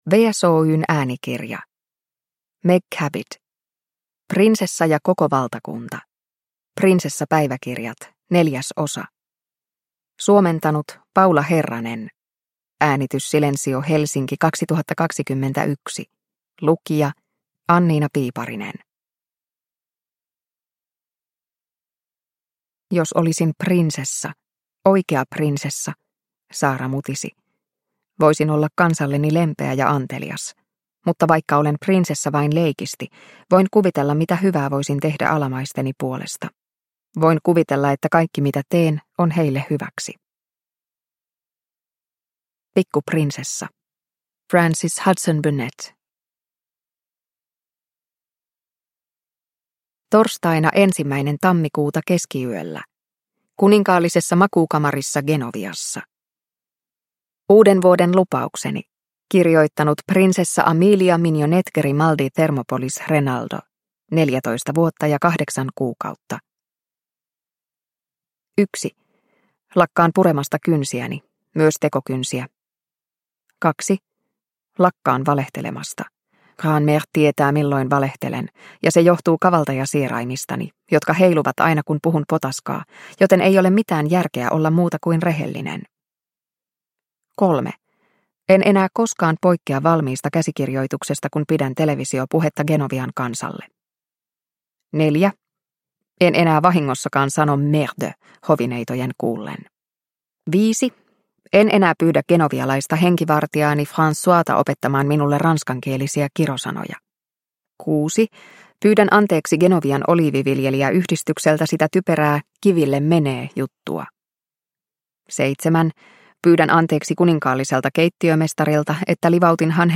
Prinsessa ja koko valtakunta – Ljudbok – Laddas ner